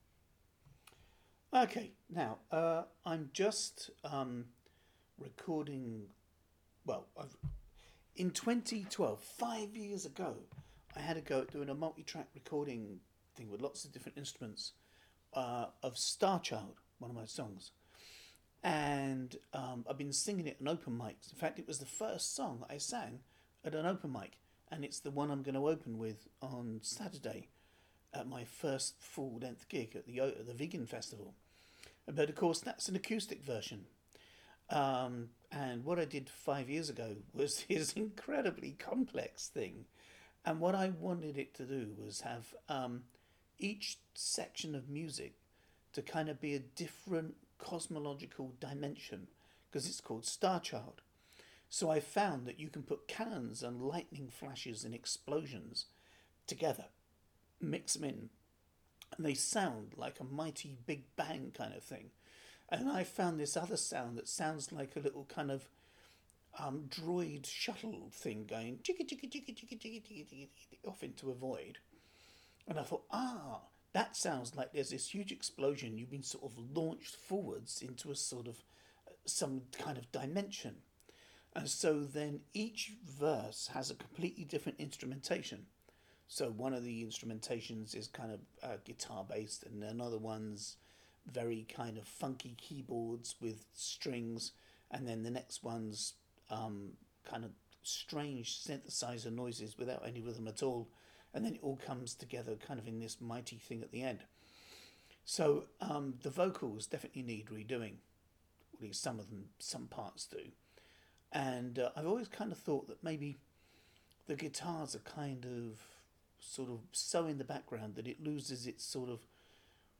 guitar track from 2012